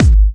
VEC3 Bassdrums Trance 52.wav